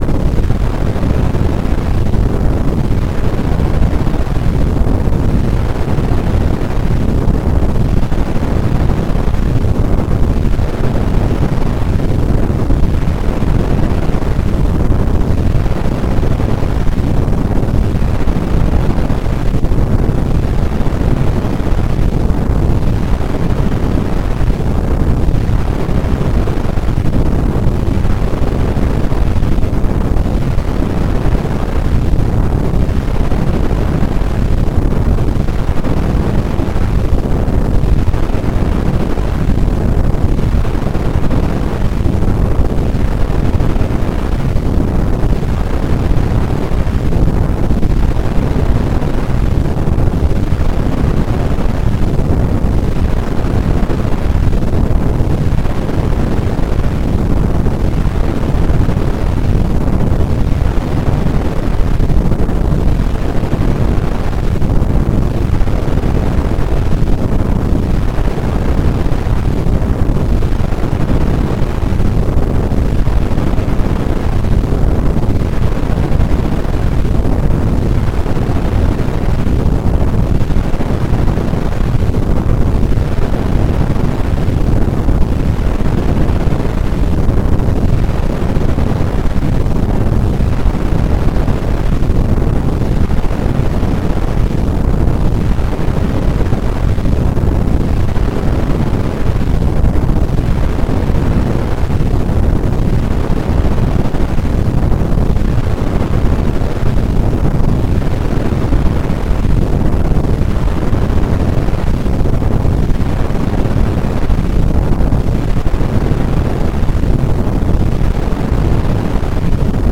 ssc_thruster1w.wav